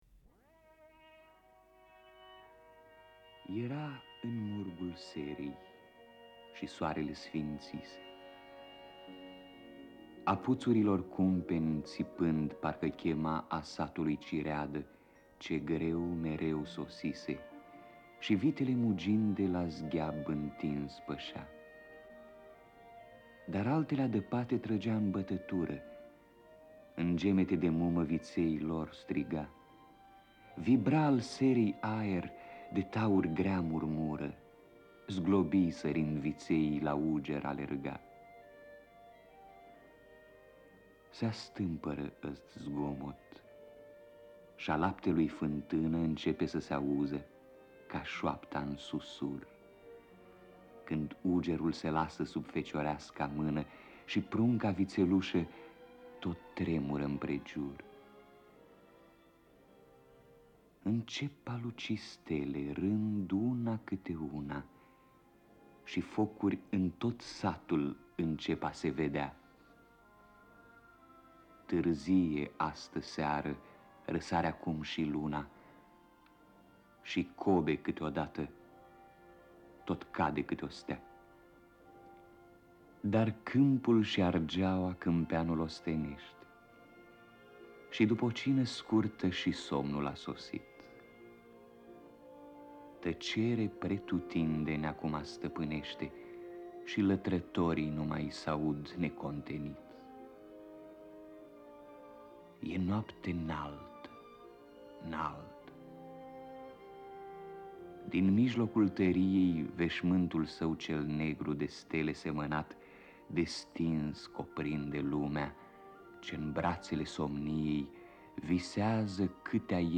• radioprogramma's